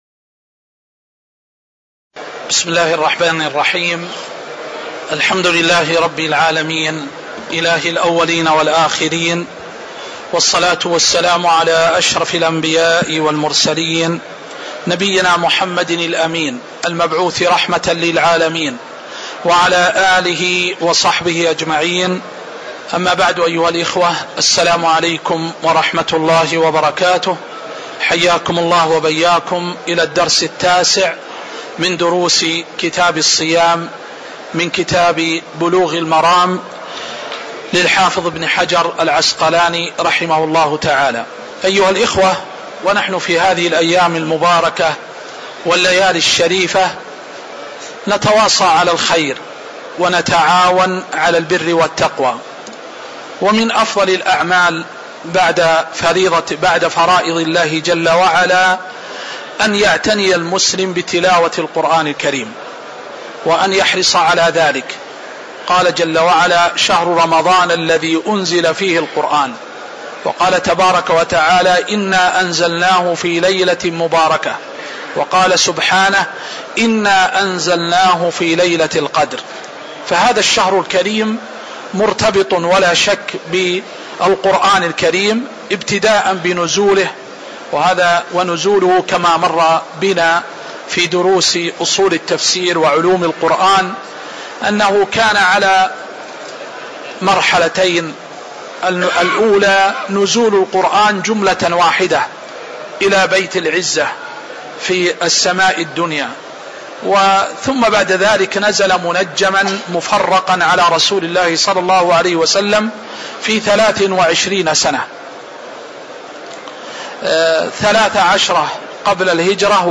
تاريخ النشر ١٢ رمضان ١٤٤٤ هـ المكان: المسجد النبوي الشيخ